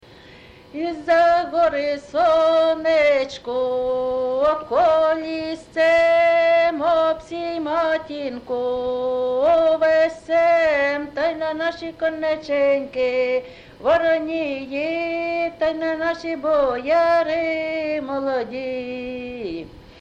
ЖанрВесільні
Місце записус. Яблунівка, Костянтинівський (Краматорський) район, Донецька обл., Україна, Слобожанщина